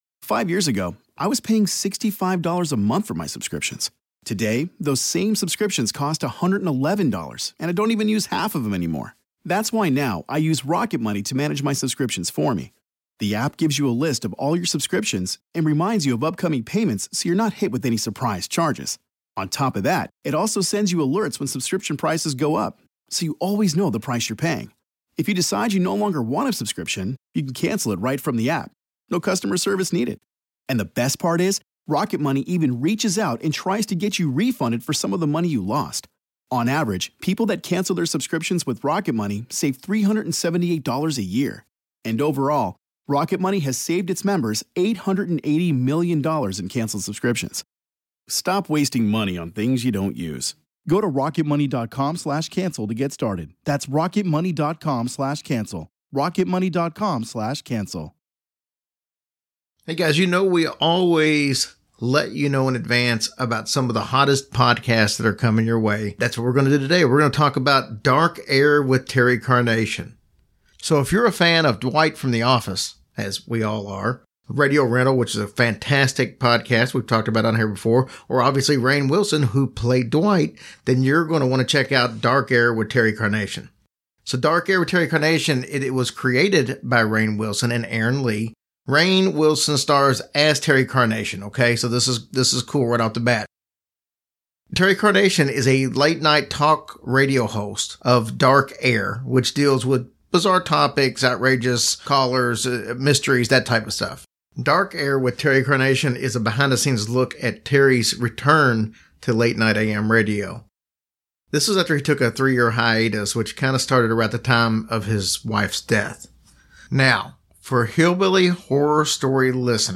Dark Air with Terry Carnation is a brand new podcast from Audioboom that premieres April 1st. Dark Air with Terry Carnation stars Rainn Wilson (The Office), Karan Soni (Deadpool), Al Madrigal (The Daily Show), and Yvette Nicole Brown (Community).